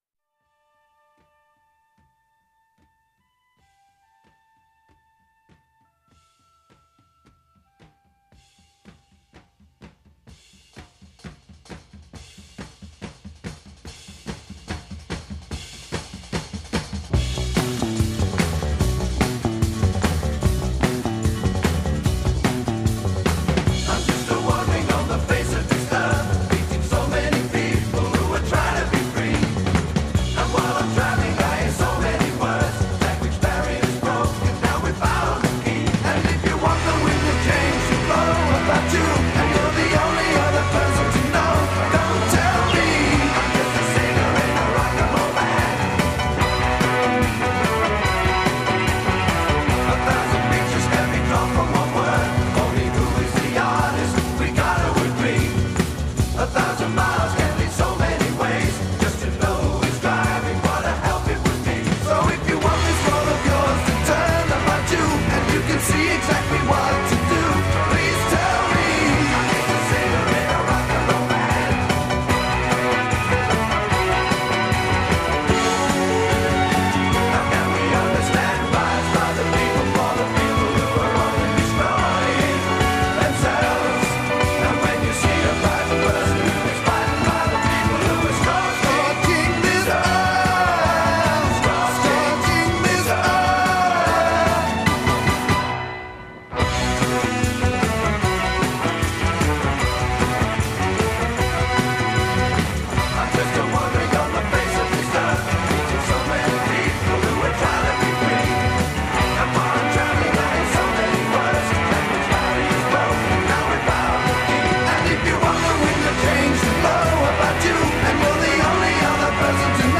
Gimme that old time rock and roll orchestra music!